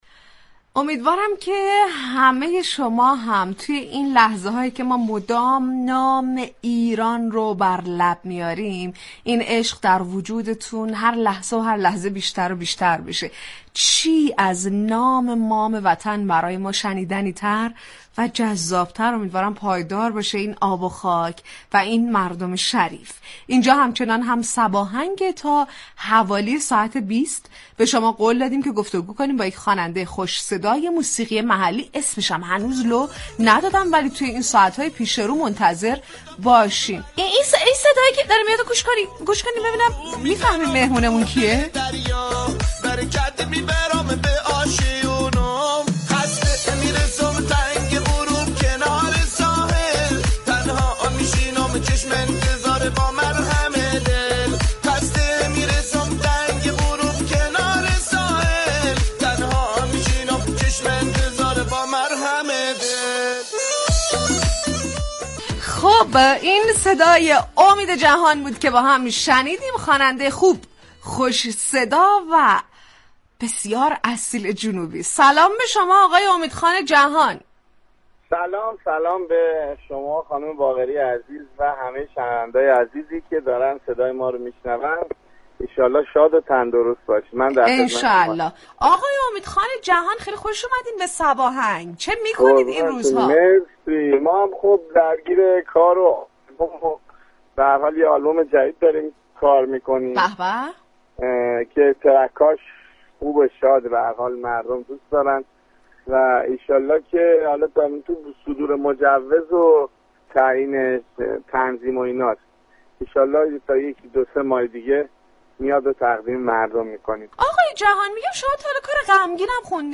این برنامه روز سه شنبه سوم آبان میزبان تلفنی امید جهان خواننده جنوبی كشورمان شد.